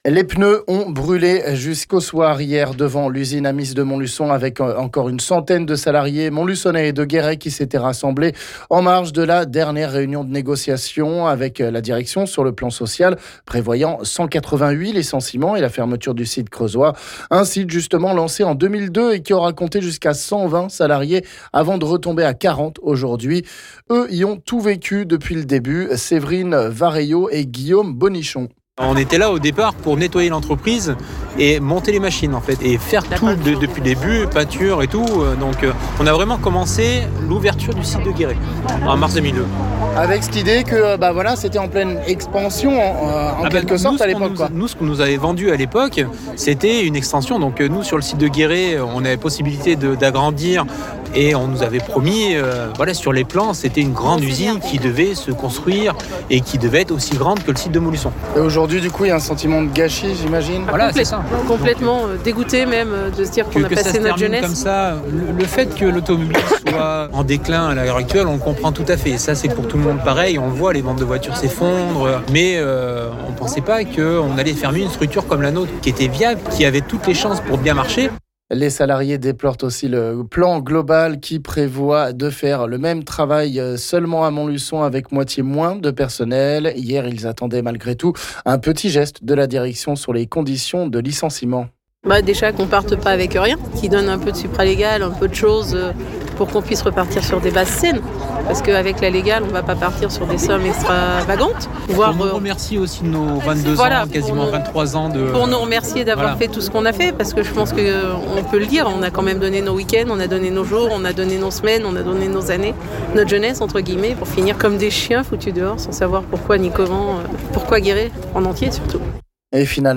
On écoute 2 salariés creusois ici...